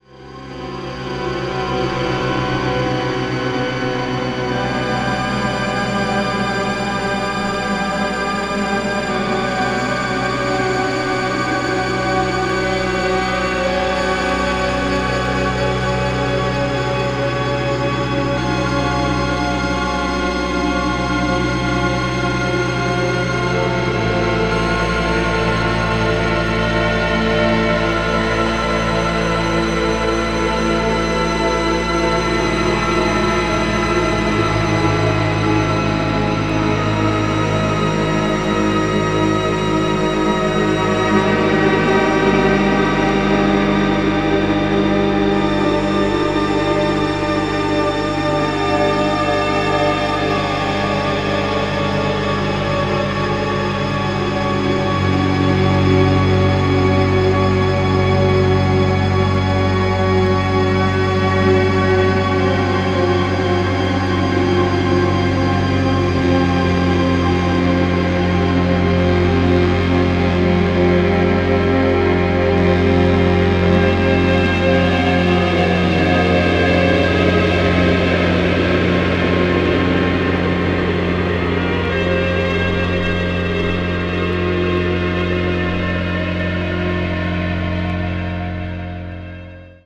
まさにベルリン・スクールな深層電子音楽。